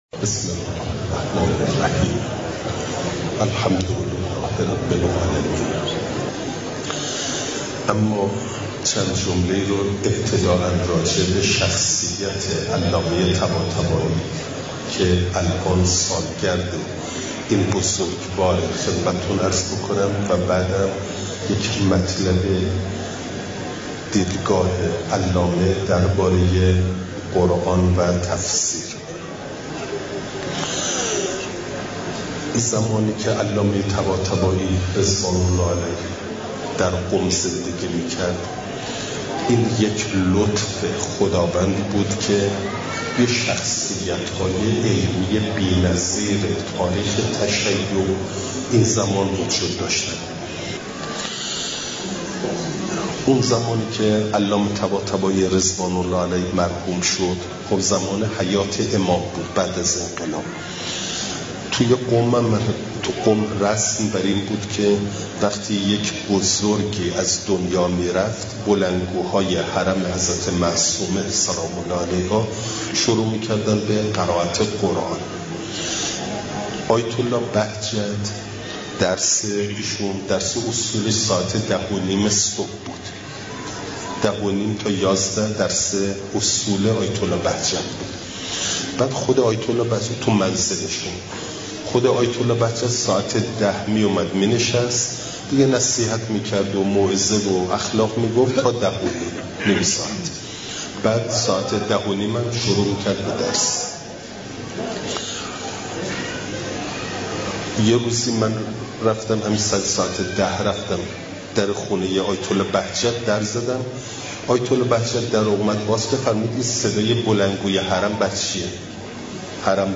چهارشنبه ۲۱ آبانماه ۱۴۰۴، باغملک، استان خوزستان